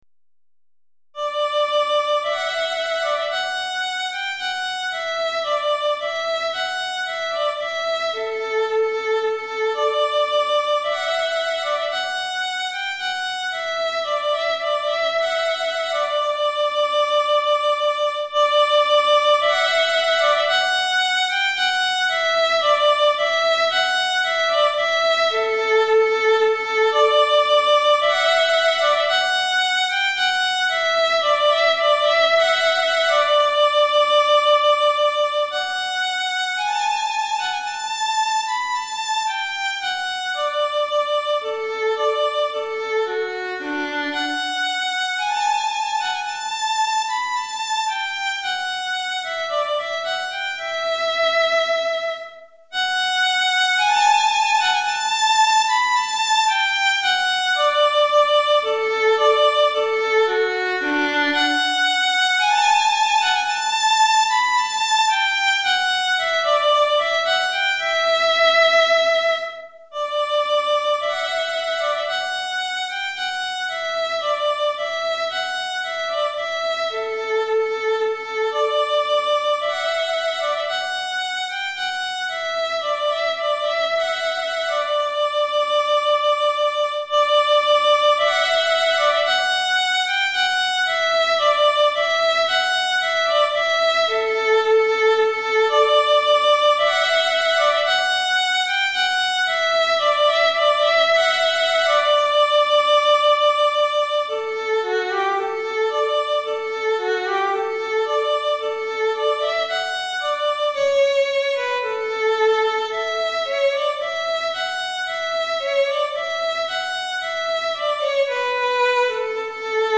Répertoire pour Violon - Violon Solo